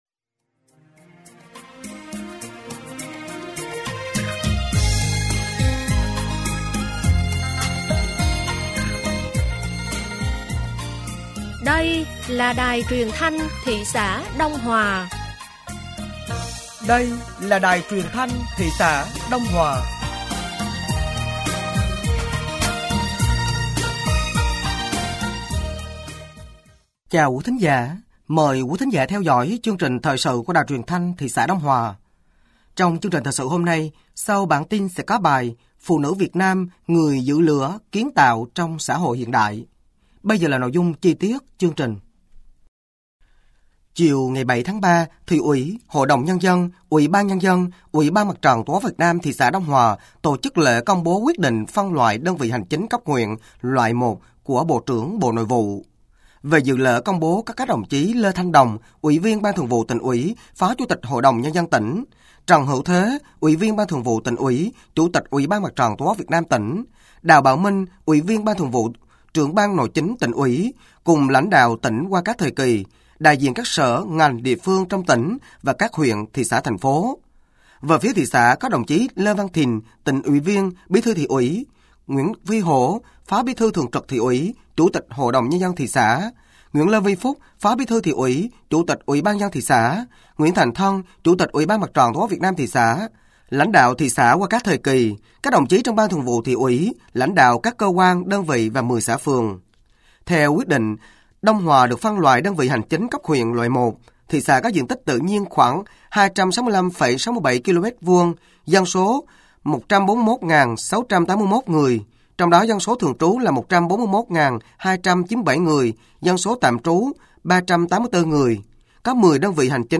Thời sự tối ngày 08 và sáng ngày 09 tháng 3 năm 2025